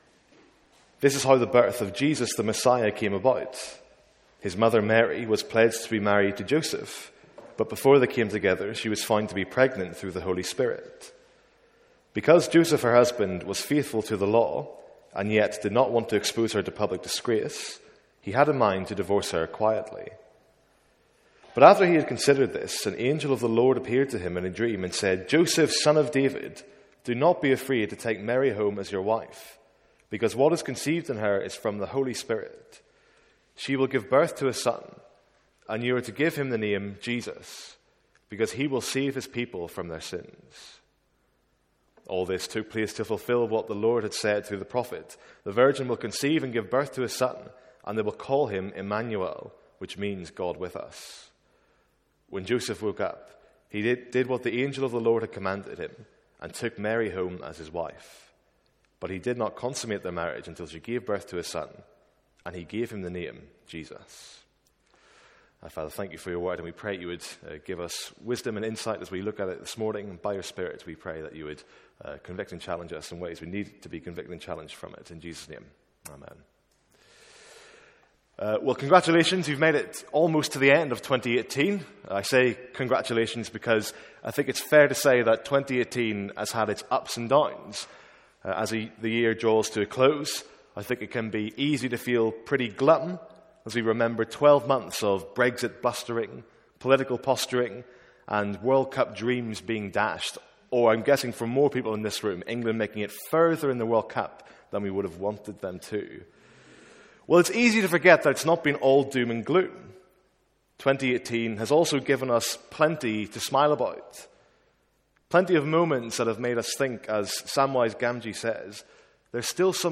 A one off Sunday morning sermon on Matthew 1:28-25.